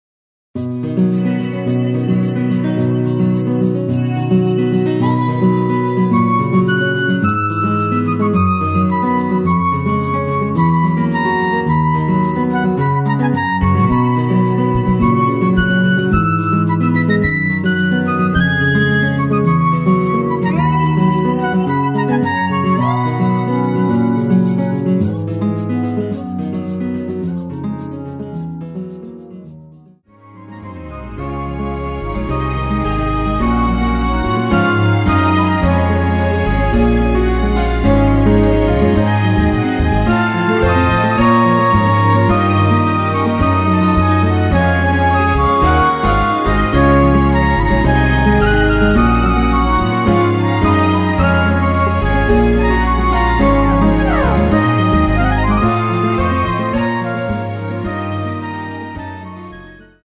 엔딩이 페이드 아웃이라 라이브 하시기 좋게 엔딩을 만들어놓았습니다.
원키에서(-1)내린 멜로디 포함된 MR입니다.
앞부분30초, 뒷부분30초씩 편집해서 올려 드리고 있습니다.